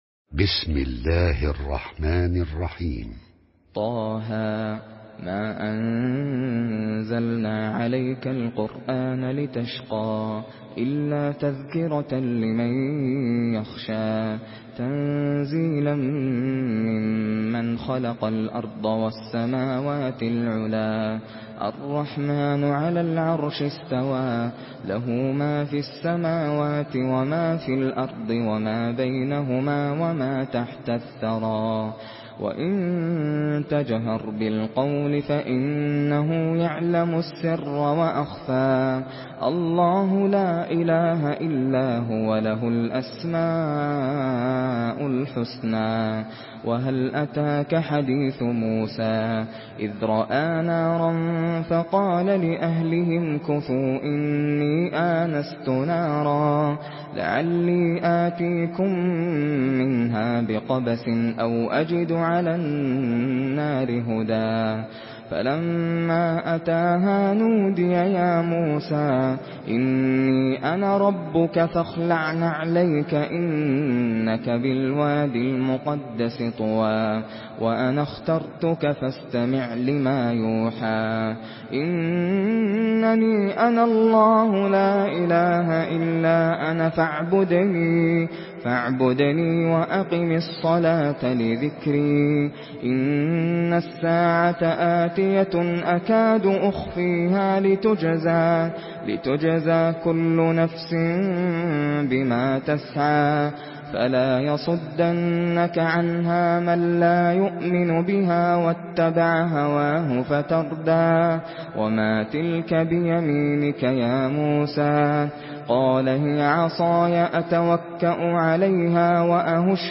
سورة طه MP3 بصوت ناصر القطامي برواية حفص
مرتل